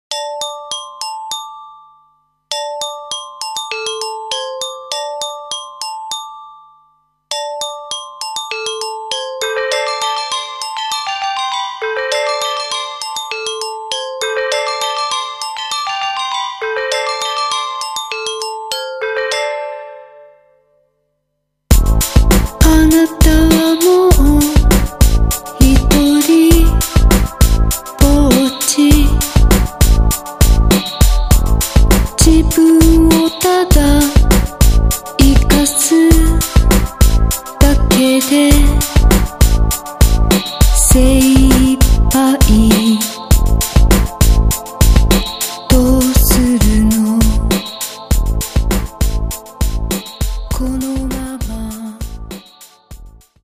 Cyberpunk + Industrial + Goth + Deathrock